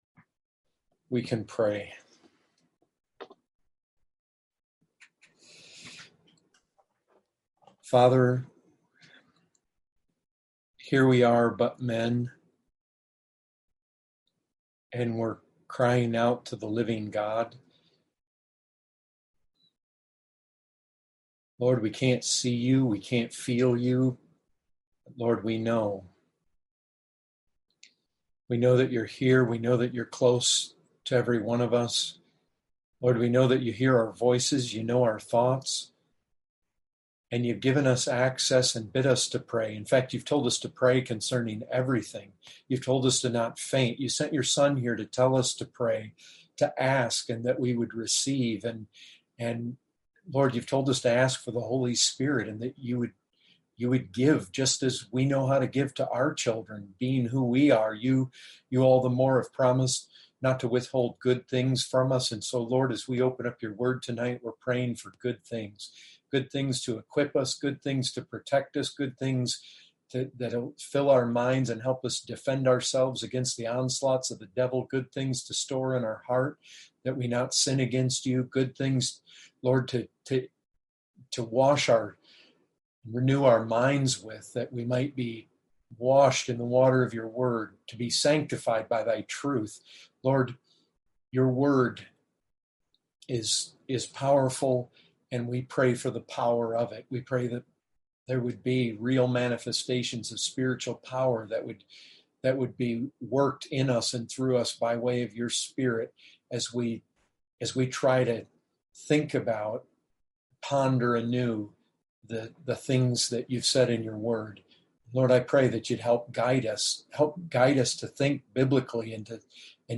2020 Category: Full Sermons Topic